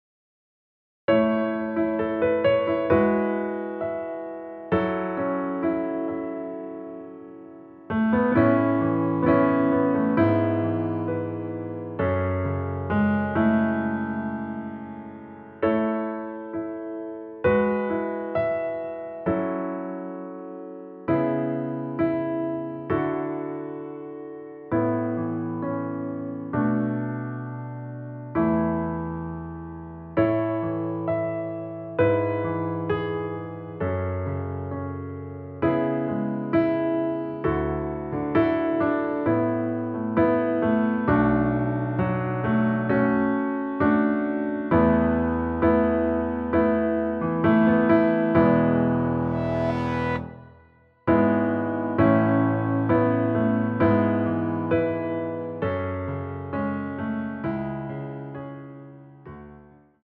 원키에서(-2)내린 MR입니다.
Ab
앞부분30초, 뒷부분30초씩 편집해서 올려 드리고 있습니다.
중간에 음이 끈어지고 다시 나오는 이유는